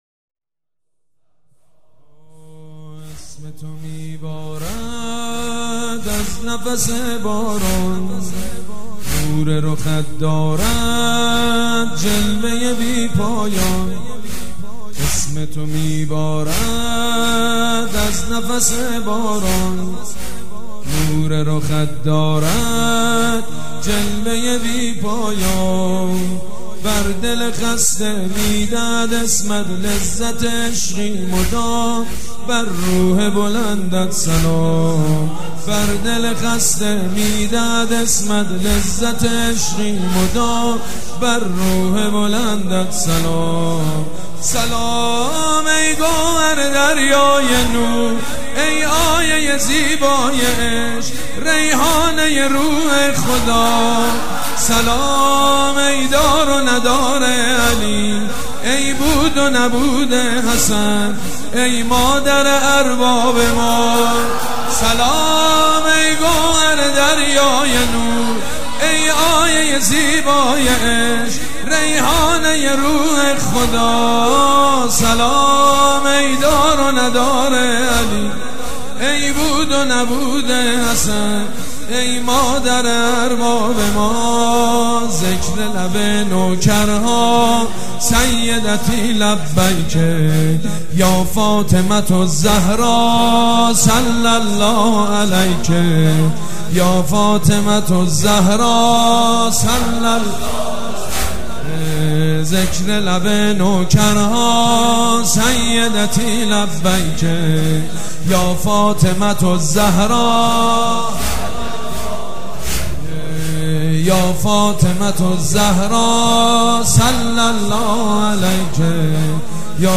متن نوحه